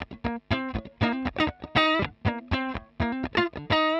120_Guitar_funky_riff_C_5.wav